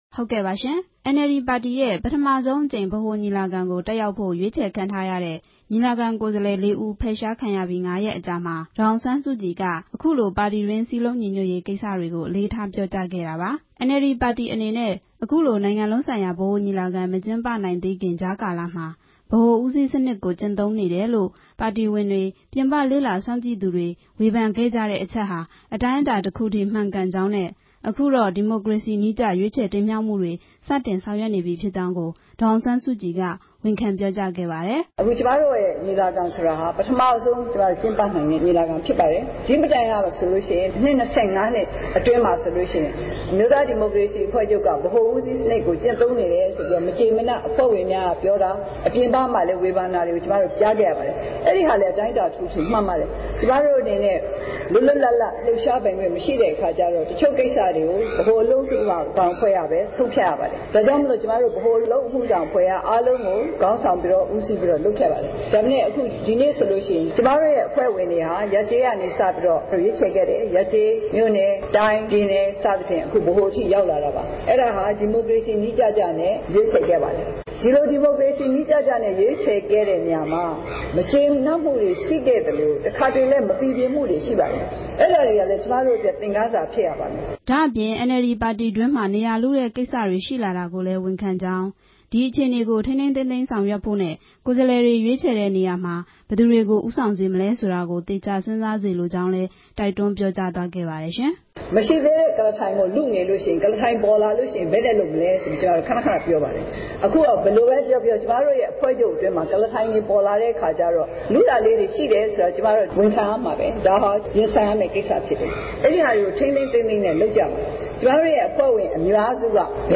ရန်ကုန်မြို့၊ ရွှေဂုံတိုင်၊ တော်ဝင်နှင်းဆီ စားသောက်ဆိုင်မှာ ဒီကနေ့ မတ်လ ၉ ရက်နေ့ ဒုတိယမြောက်နေ့အဖြစ်ကျင်းပတဲ့ အမျိုးသားဒီမိုကရေစီအဖွဲ့ချုပ်ရဲ့ နိုင်ငံလုံးဆိုင်ရာ ညီလာခံမှာ ဒေါ်အောင်ဆန်းစုကြည် ပြောကြားခဲ့တာဖြစ် ပါတယ်။